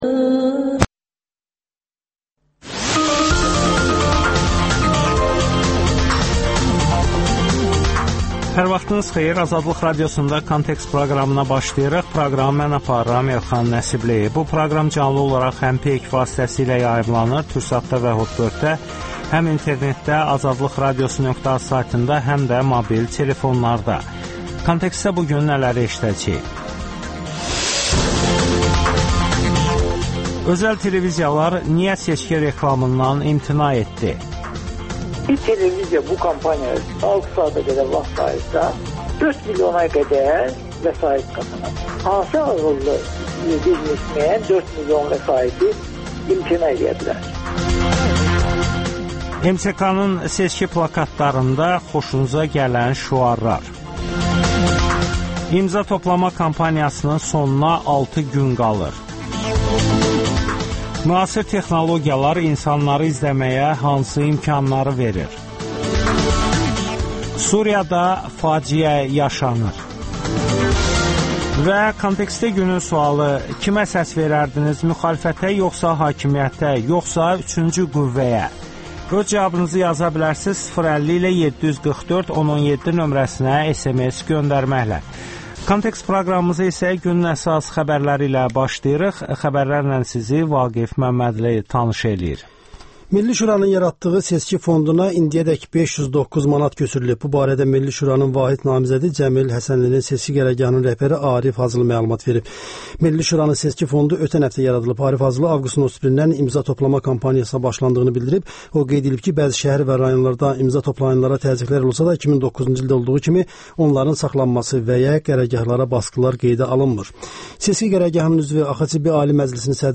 Bakı sakinləri seçki plakatlarından danışır.